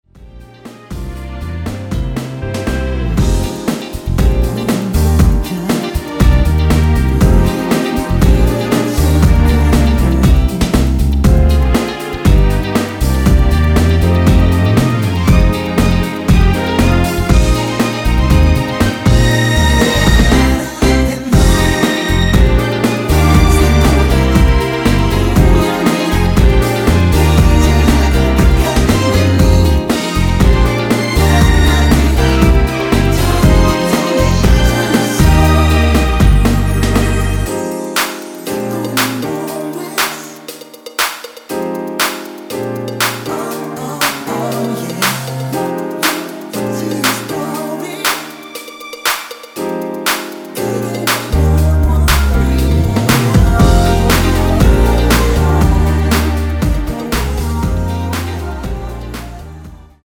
원키에서(+1)올린 코러스 포함된 MR입니다.
Eb
앞부분30초, 뒷부분30초씩 편집해서 올려 드리고 있습니다.
중간에 음이 끈어지고 다시 나오는 이유는